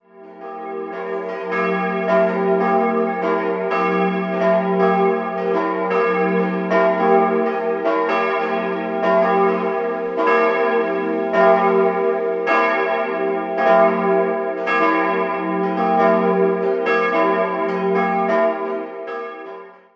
Im Inneren sind besonders die drei Altäre sehenswert, vor allem der Rokokohochaltar, der aus einer säkularisierten Kapelle in Ingolstadt stammt. 3-stimmiges TeDeum-Geläute: e'-g'-a' Die Glocken wurden 1922 vom Bochumer Verein für Gussstahlfabrikation gegossen.